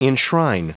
Prononciation du mot enshrine en anglais (fichier audio)